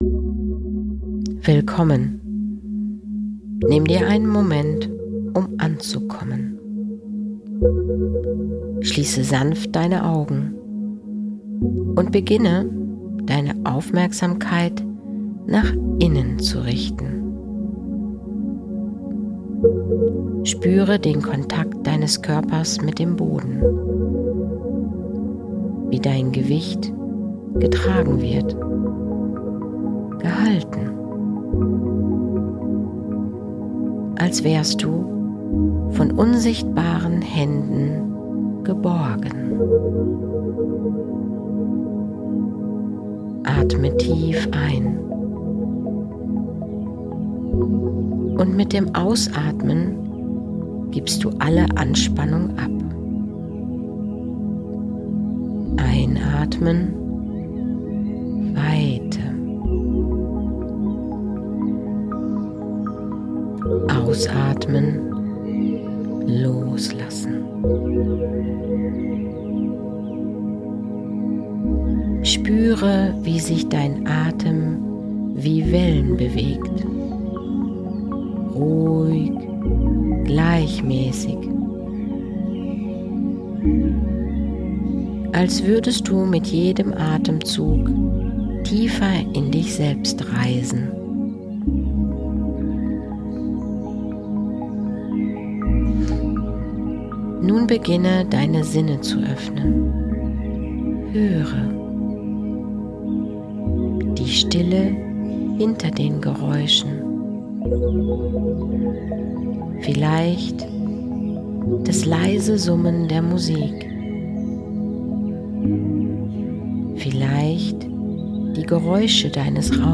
Diese geführte Meditation ist eine kraftvolle Einladung, dich tief mit deinem Seelenursprung zu verbinden – jenseits von Raum, Zeit und Form. In einem tranceähnlichen Zustand öffnest du dich deinem inneren Licht, empfängst Botschaften aus der Quelle und erinnerst dich an deine ureigene Frequenz.